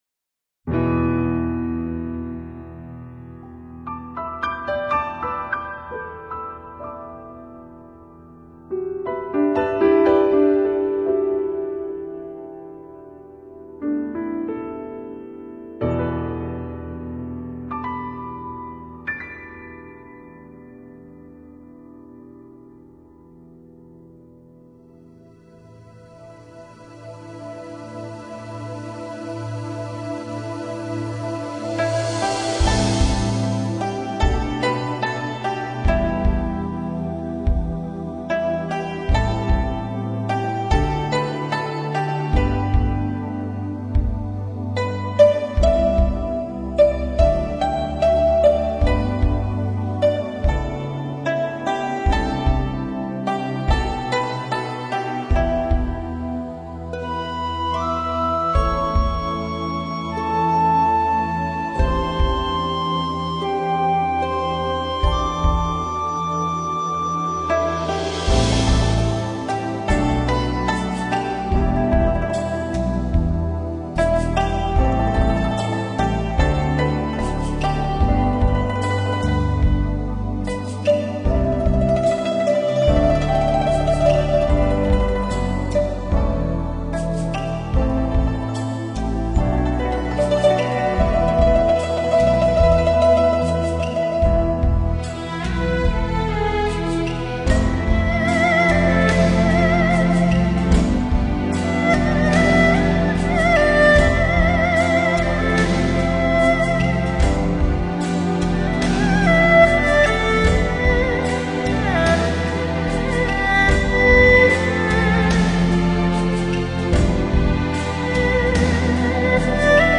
类型：NEWAGE